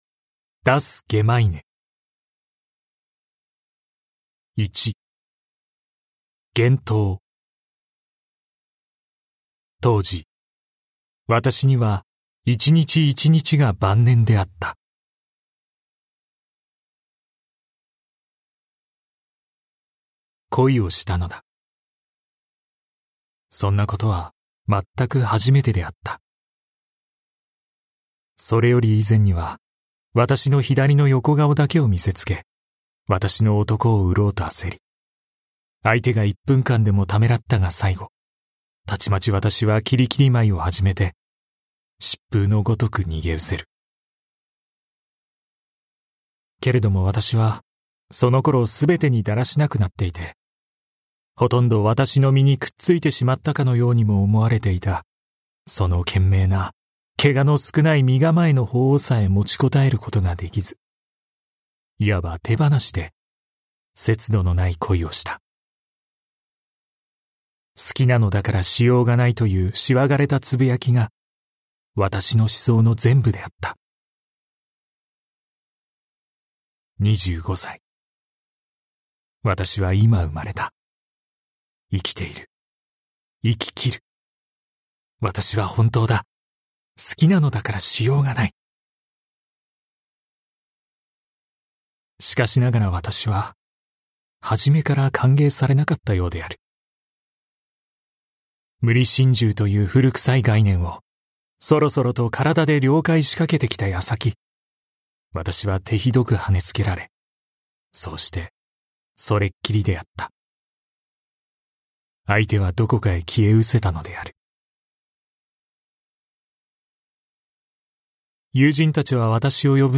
[オーディオブック] ダス・ゲマイネ